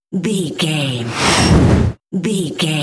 Whoosh fast jet bright
Sound Effects
Fast
futuristic
whoosh